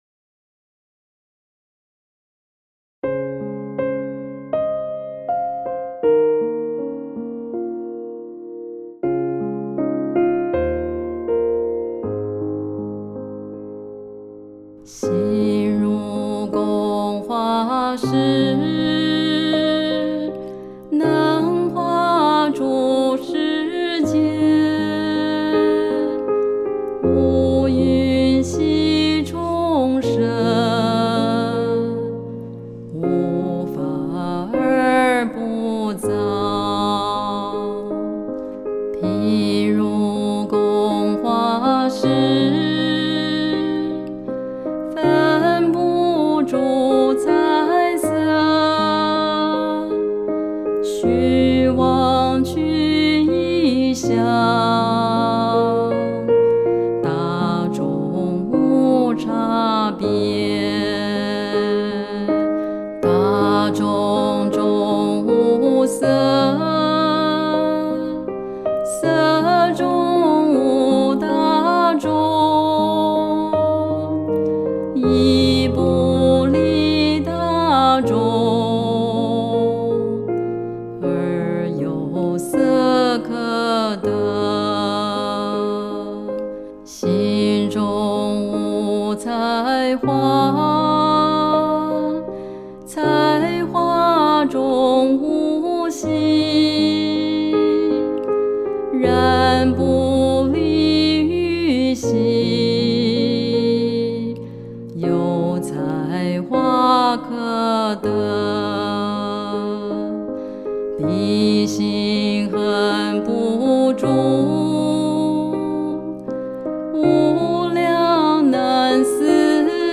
鋼琴配樂